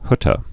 (hŭtə, häthə)